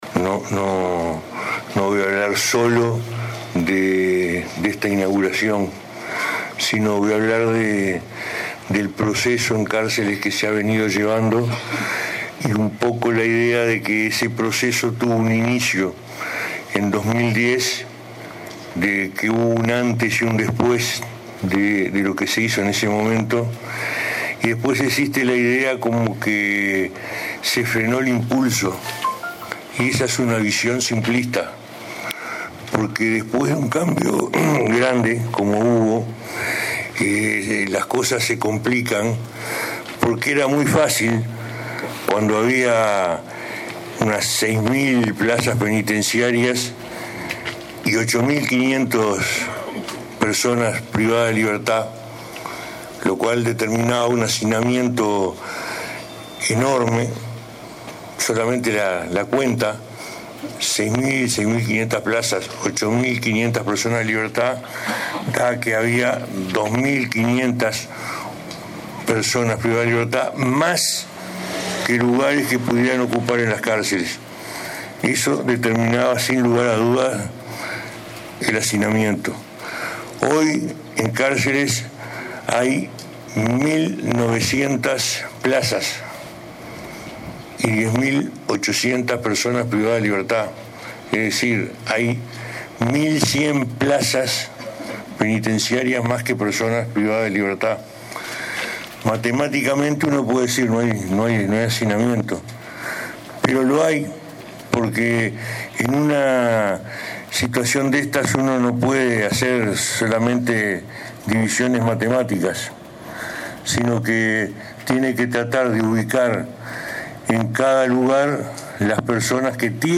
El ministro del Interior, Eduardo Bonomi, inauguró este viernes la cárcel de Florida. En ese marco, dijo que hay 11.900 plazas y 10.800 personas privadas de libertad, lo que implica 1.100 plazas más que internos.